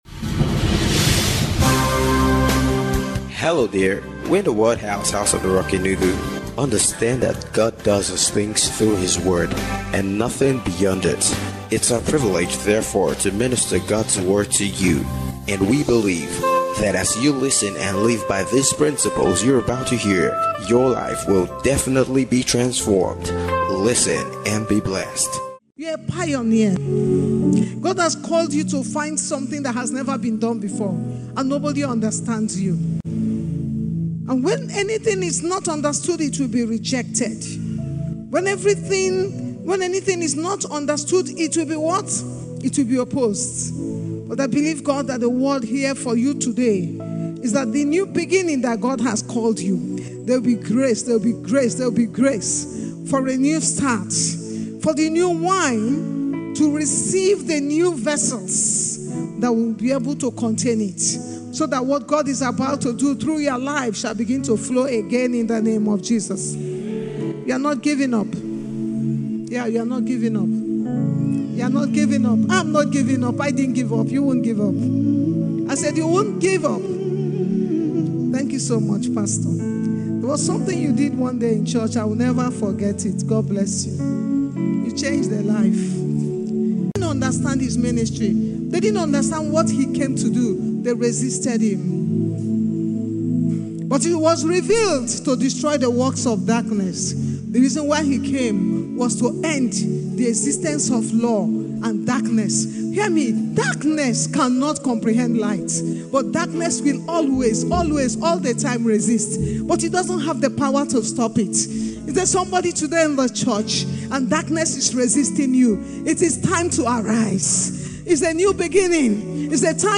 IGNITE CONFERENCE 2026 - DAY 2